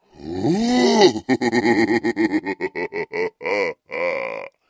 Здесь собраны аудиозаписи, передающие шаги, голоса и атмосферу этих гигантских существ.
Смех великана звучит громко